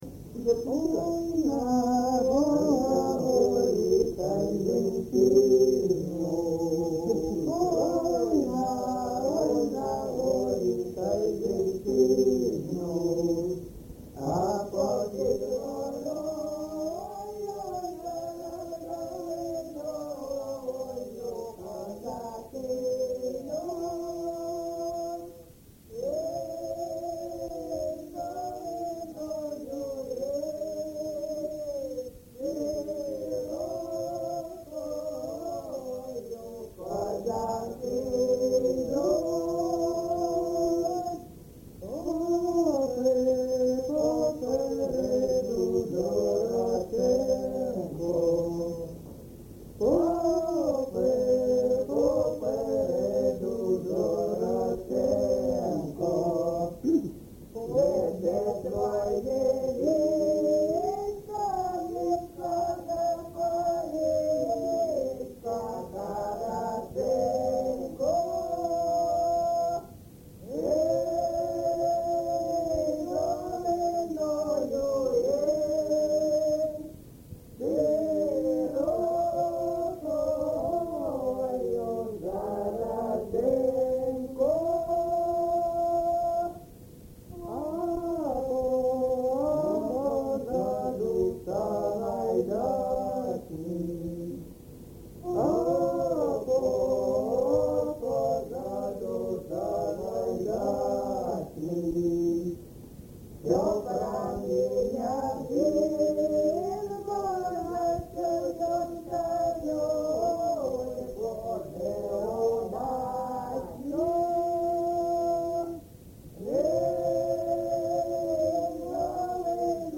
ЖанрКозацькі, Історичні
Місце записум. Часів Яр, Артемівський (Бахмутський) район, Донецька обл., Україна, Слобожанщина